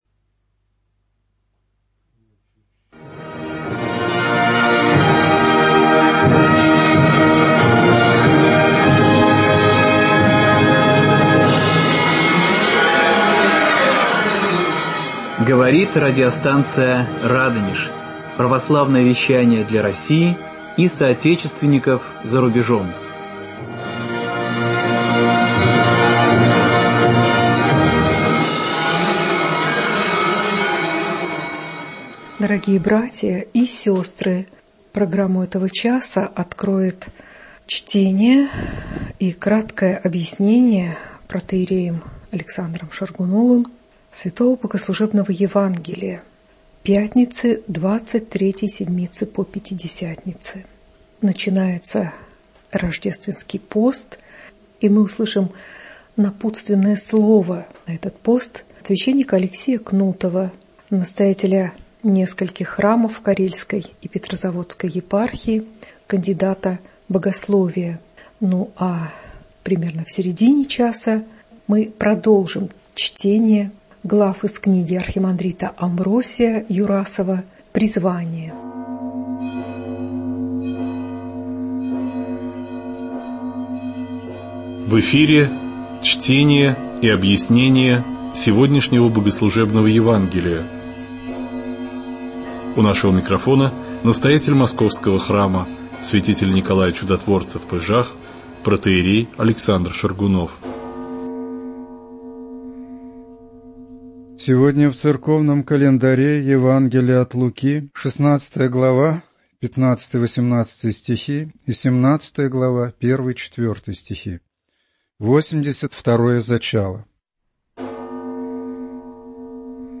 Чтение и краткое объяснение Богослужебного Евангелия пятницы 23 седмицы по Пятидесятнице